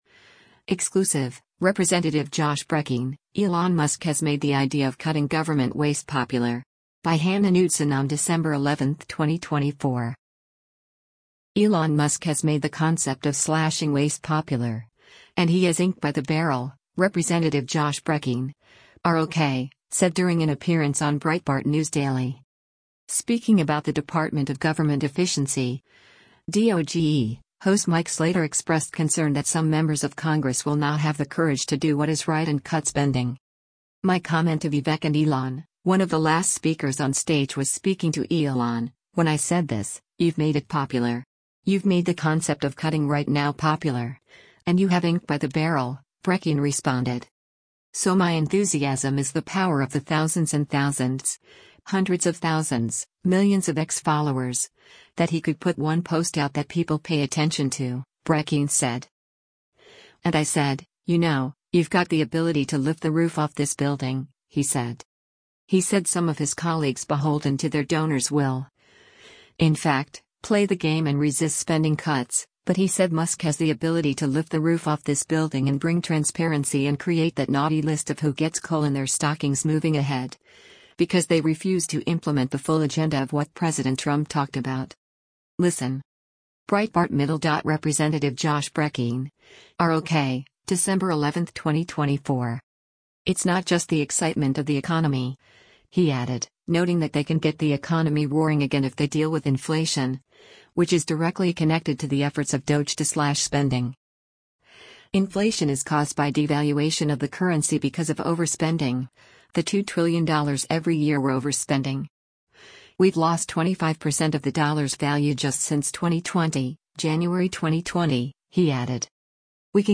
Elon Musk has made the concept of slashing waste popular, and he has “ink by the barrel,” Rep. Josh Brecheen (R-OK) said during an appearance on Breitbart News Daily.